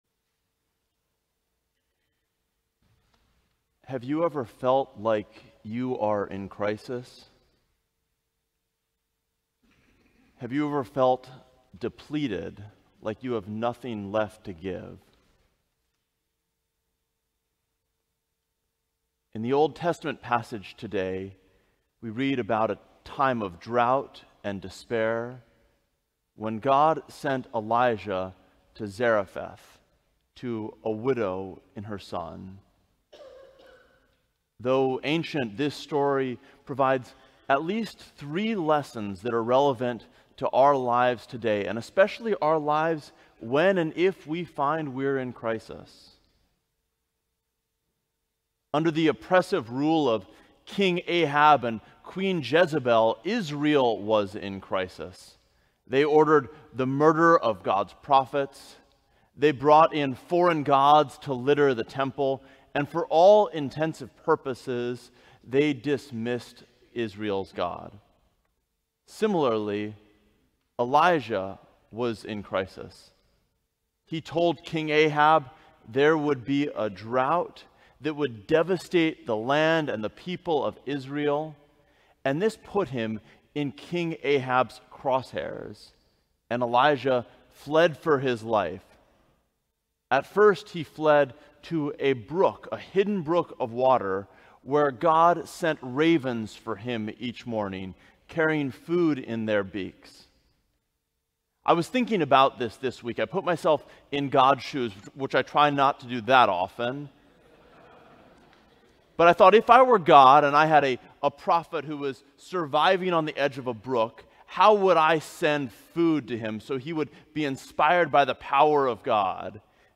Sermon: Surprises in Struggles - St. John's Cathedral